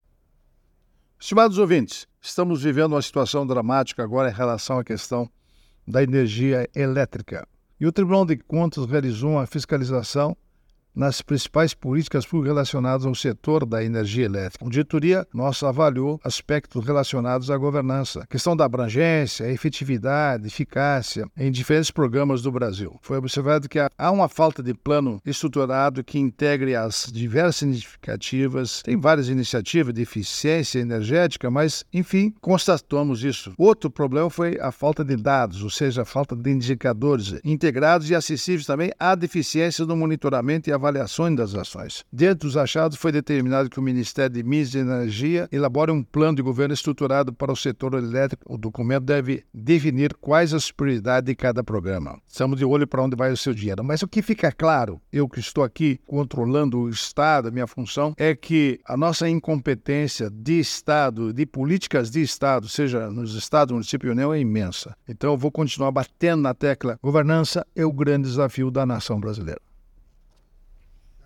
Comentário desta terça-feira (15/10/24) do ministro do TCU Augusto Nardes.